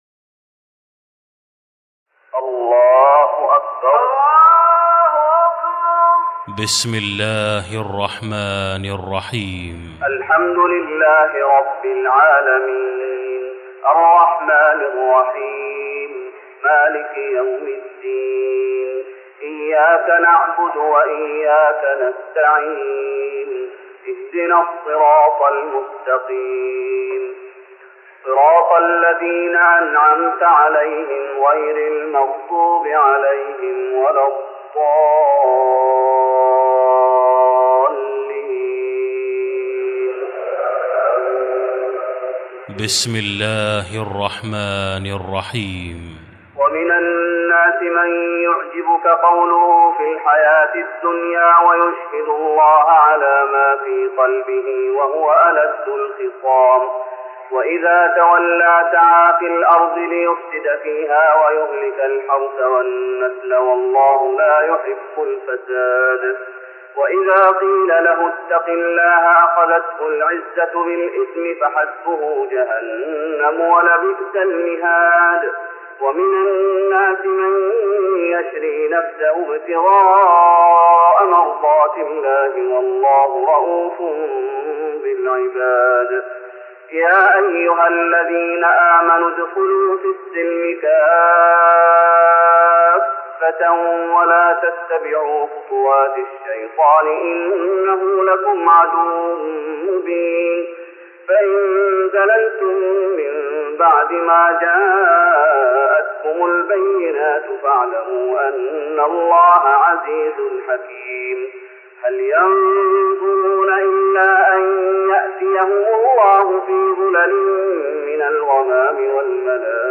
تراويح رمضان 1414هـ من سورة البقرة (204-253) Taraweeh Ramadan 1414H from Surah Al-Baqara > تراويح الشيخ محمد أيوب بالنبوي 1414 🕌 > التراويح - تلاوات الحرمين